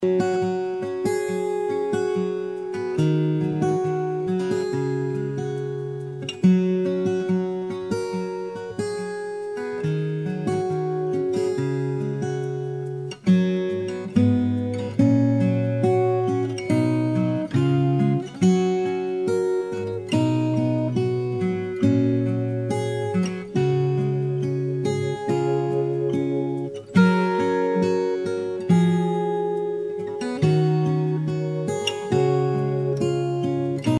B/Trax – High Key without Backing Vocals